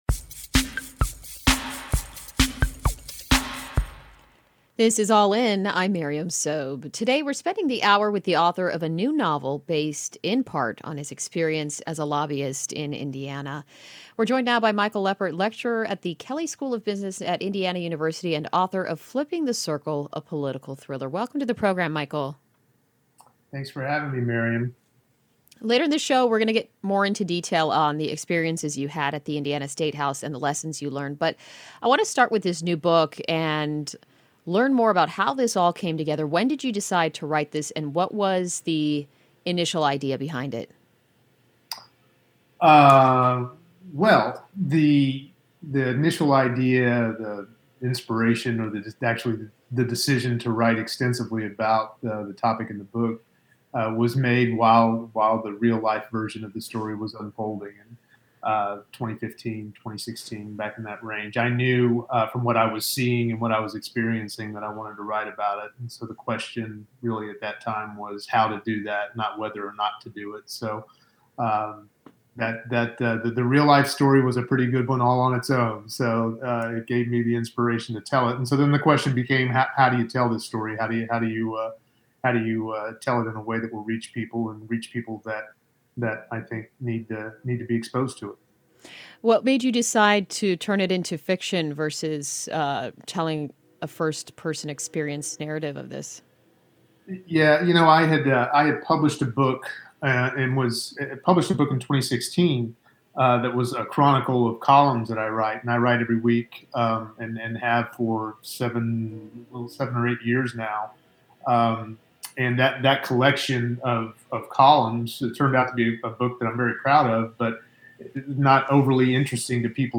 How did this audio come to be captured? Listen to the broadcast version of the show